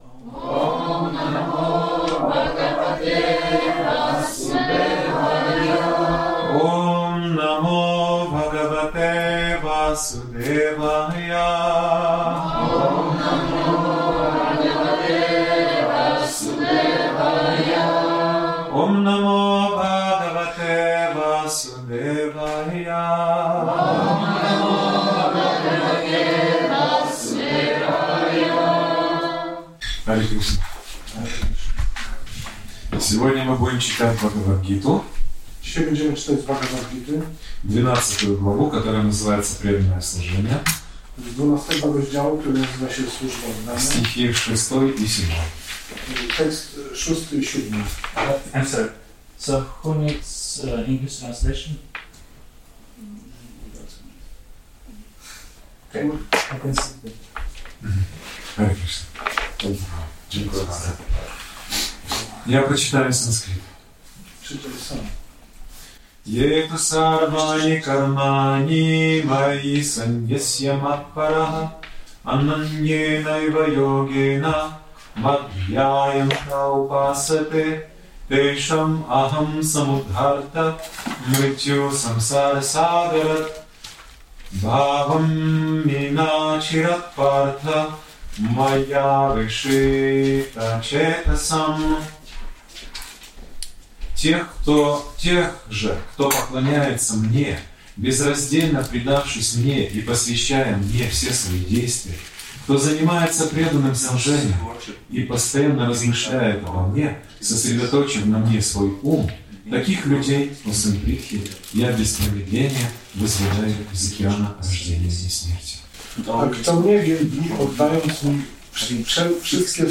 Wykład na podstawie Bhagavad-gity 12.6-7 nagrany 8 lutego 2026 roku. Język rosyjski z tłumaczeniem na język polski.